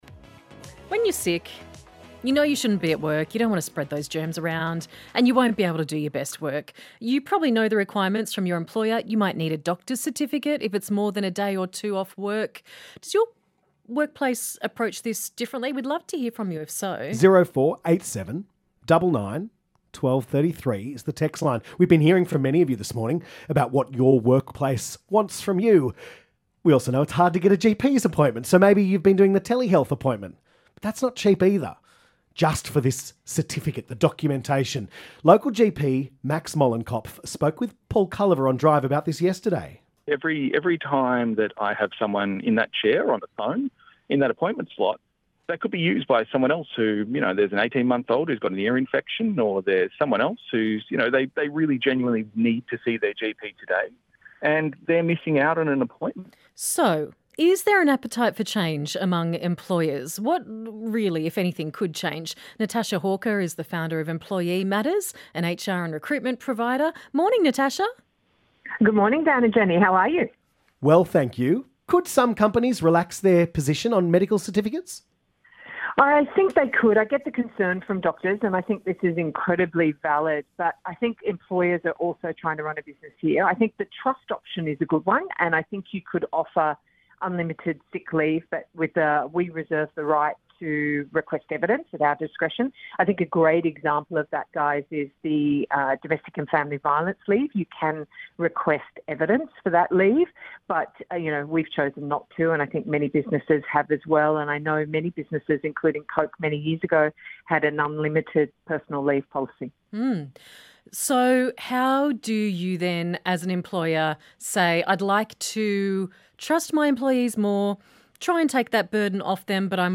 ABC-Newcastle-June-4-2024.mp3